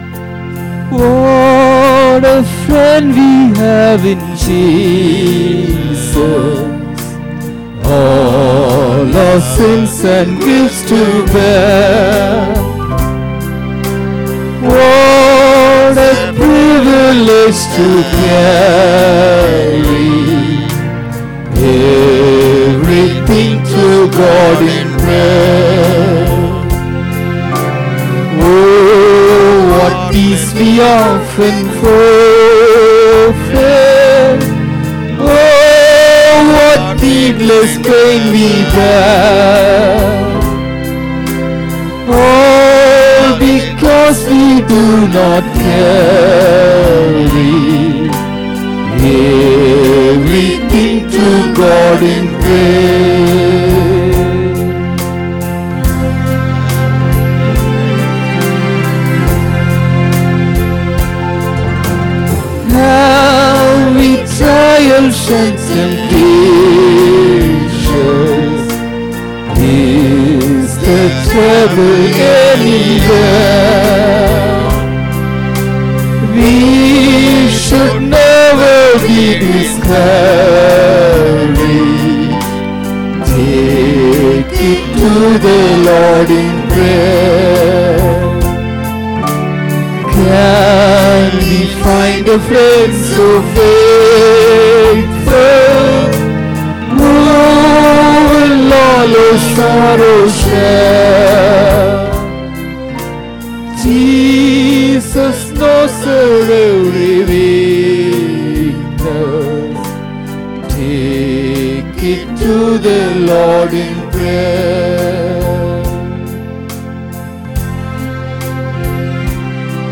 17 Aug 2025 Sunday Morning Service – Christ King Faith Mission
Service Type: Sunday Morning Service